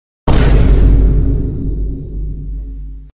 Vine Boom Sound Effect Free Download
Vine Boom